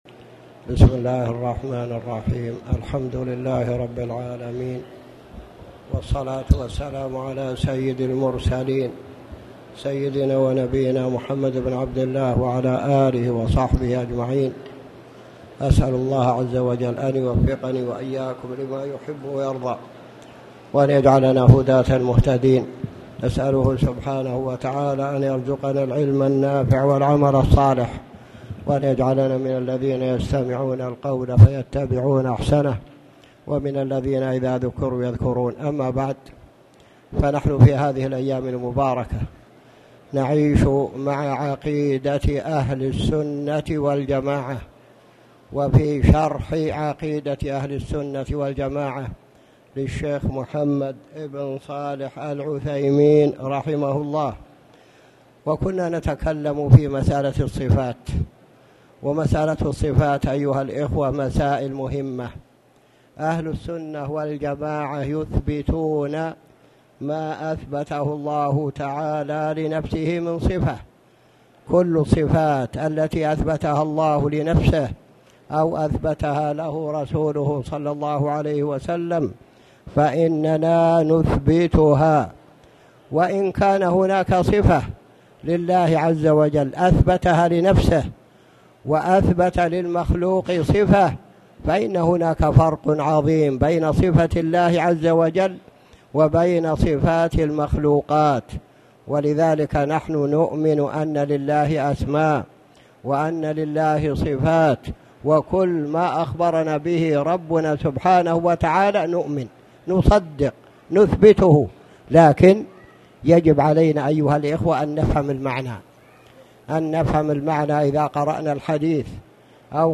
تاريخ النشر ١٧ شعبان ١٤٣٨ هـ المكان: المسجد الحرام الشيخ